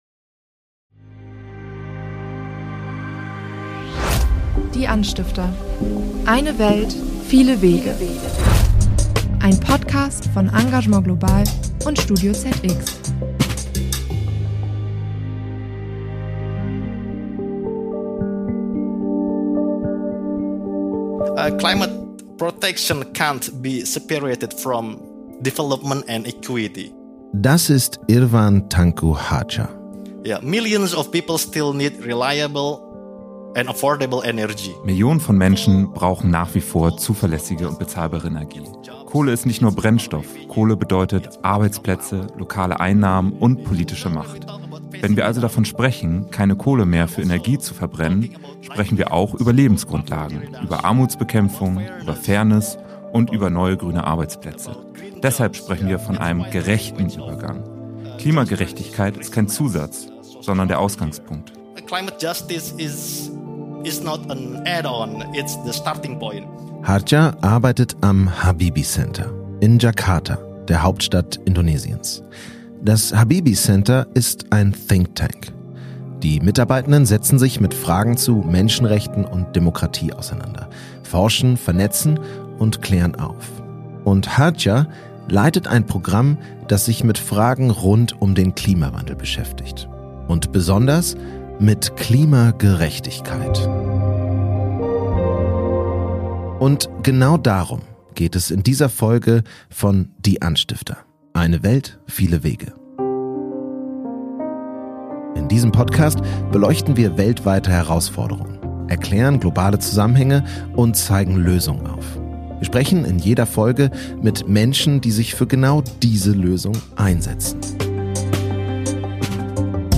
Beschreibung vor 5 Monaten Während der Globale Süden die Folgen des Klimawandels besonders stark spürt, tragen vor allem die Industrieländer – der Globale Norden – die Verantwortung dafür. Warum Klimagerechtigkeit im Kampf gegen die Erderwärmung deshalb unverzichtbar ist und was genau dahintersteckt, erklärt Prof. Dr. Dirk Messner vom Umweltbundesamt, Deutschlands zentraler Umweltbehörde, im Interview dieser Folge.